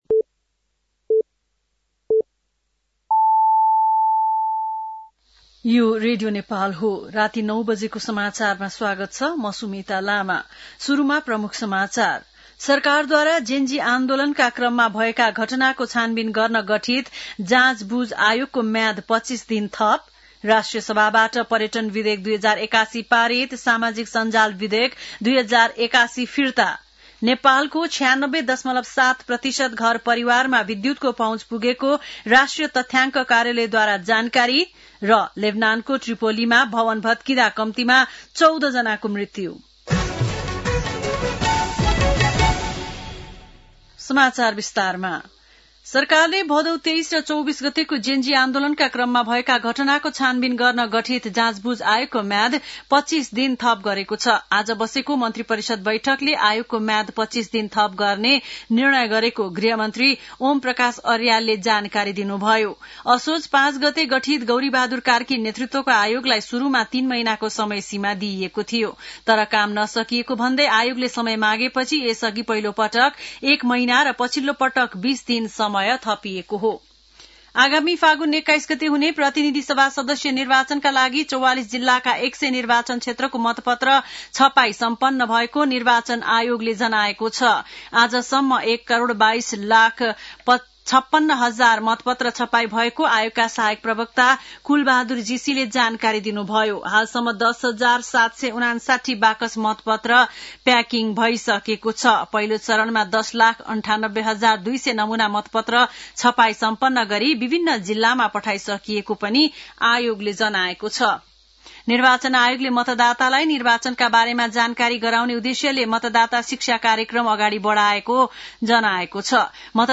बेलुकी ९ बजेको नेपाली समाचार : २६ माघ , २०८२
9-PM-Nepali-NEWS-10-26.mp3